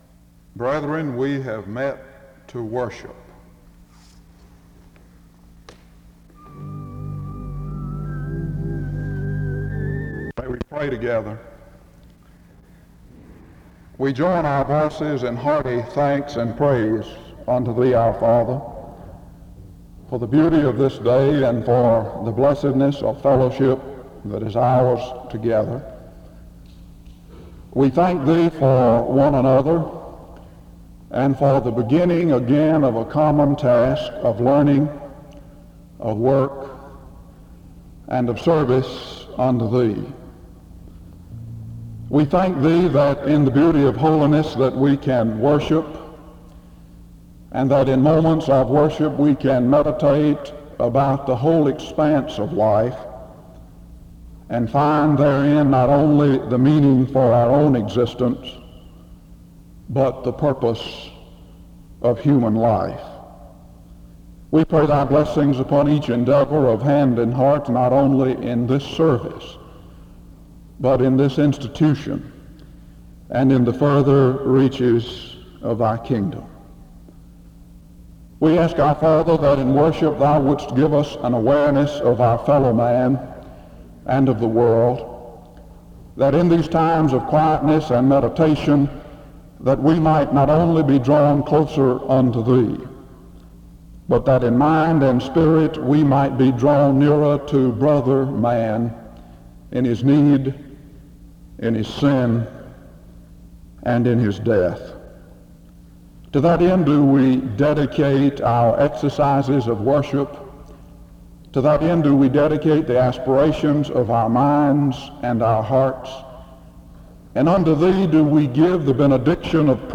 The service opens with prayer from 0:00-2:15. A responsive reading takes place from 2:21-4:23.
SEBTS Chapel and Special Event Recordings SEBTS Chapel and Special Event Recordings